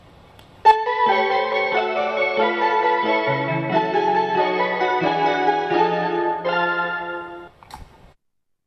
発車メロディ